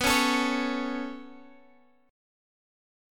Listen to B7sus2 strummed